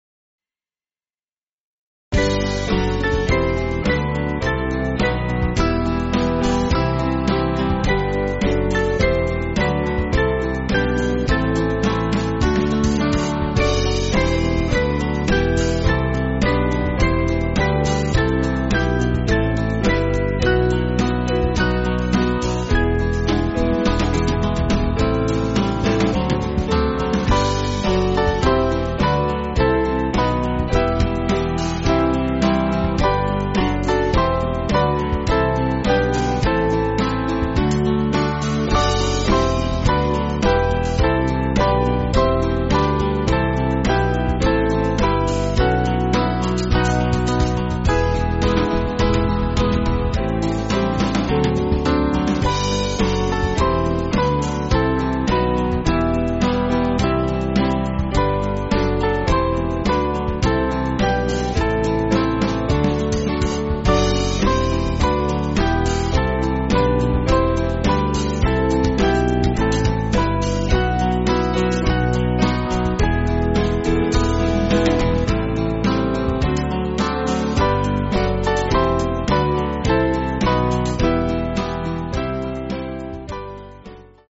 Small Band
(CM)   8/Em